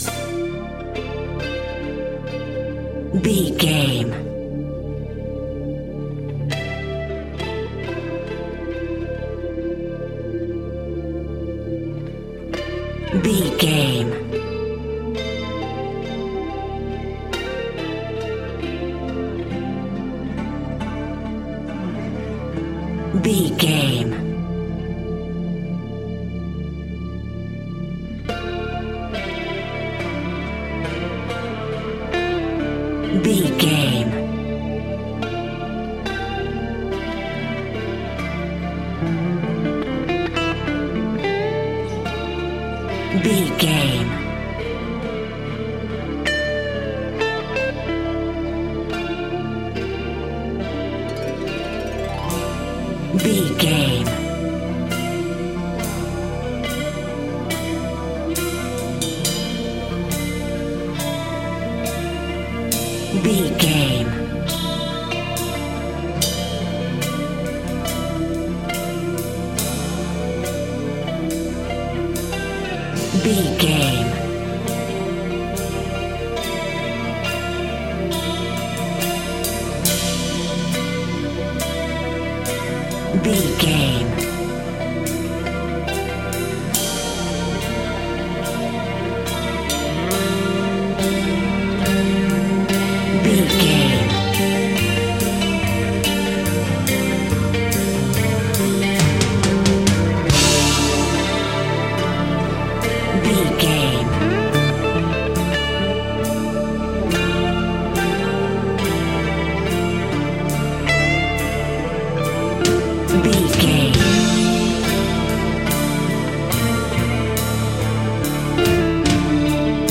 Thriller
Aeolian/Minor
Slow
synthesiser
electric guitar
tension
ominous
suspense
haunting
creepy